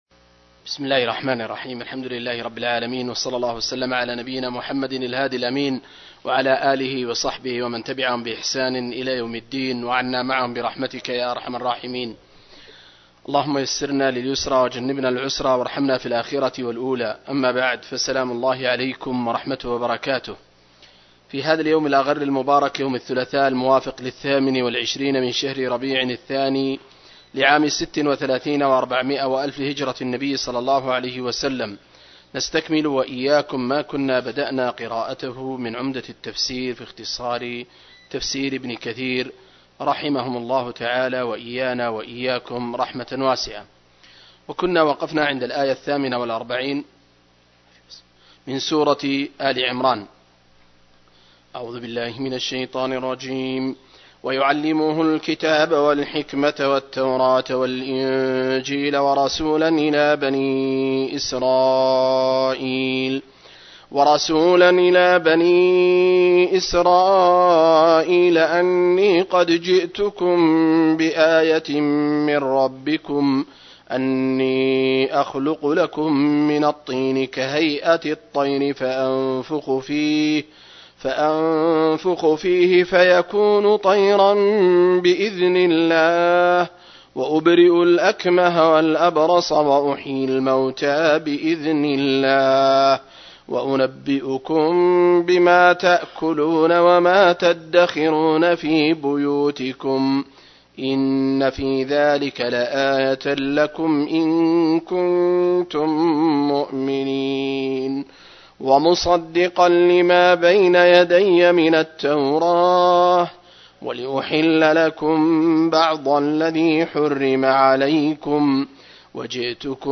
066- عمدة التفسير عن الحافظ ابن كثير رحمه الله للعلامة أحمد شاكر رحمه الله – قراءة وتعليق –